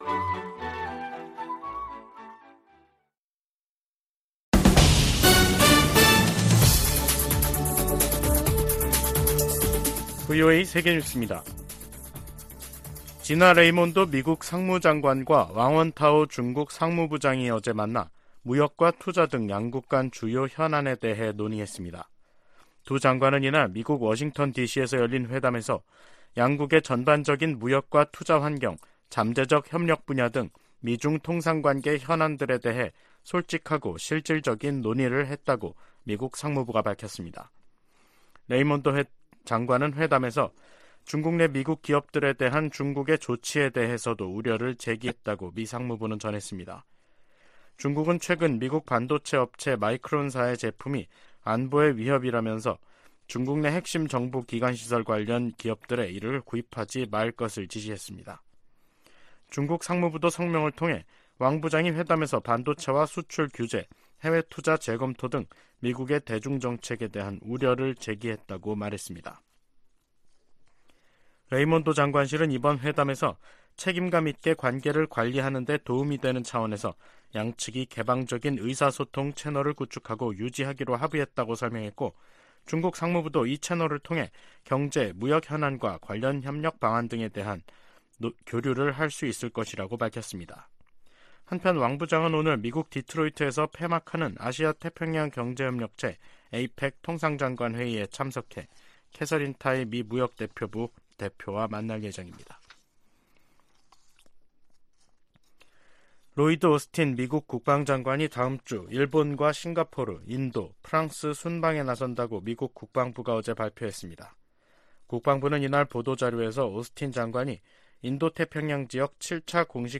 VOA 한국어 간판 뉴스 프로그램 '뉴스 투데이', 2023년 5월 26일 2부 방송입니다. 한국이 자력으로 실용급 위성을 궤도에 안착시키는데 성공함으로써 북한은 우주 기술에서 뒤떨어진다는 평가가 나오고 있습니다. 워싱턴 선언은 한국에 대한 확정억제 공약을 가장 강력한 용어로 명시한 것이라고 미 고위 당국자가 평가했습니다. 미국은 중국의 타이완 공격을 억제하기 위해 동맹국들과 공동 계획을 수립해야 한다고 미 하원 중국특별위원회가 제언했습니다.